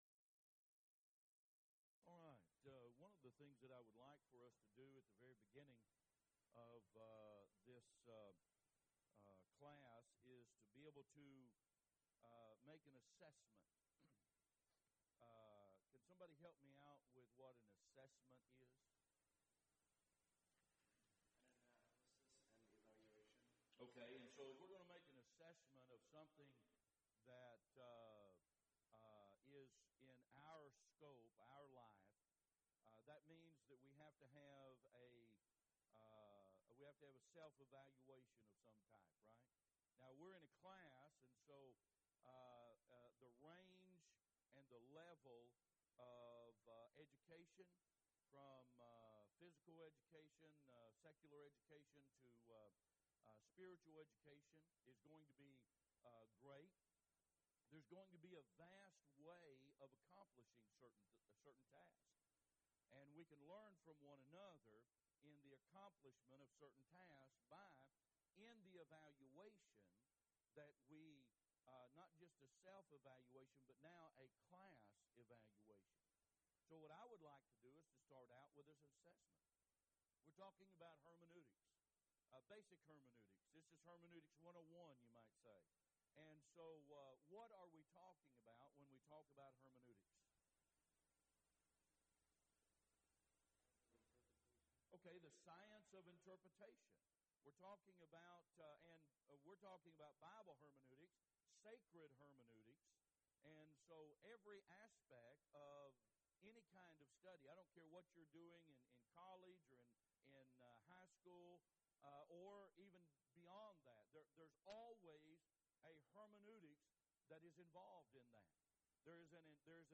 Event: 1st Annual Young Men's Development Conference
lecture